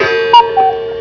cuckoo clock, shelf clock, ship's bell clock
Cuckoo.wav